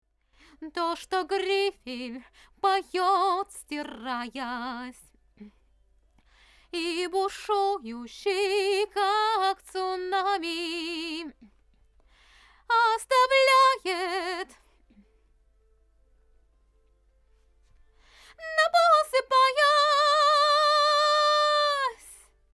Микрофон для пронзительного женского голоса.
У вокалистки очень много гармоник в голосе, когда она в верхнем регистре поёт.
А вот будучи записанными, эти верхние ноты звучат слишком резко...
Soothe, Melda MSpectralDynamics, вот, мелдой попробовал, которая вообще часто выручает в таких ситуациях.